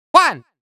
countIn1.wav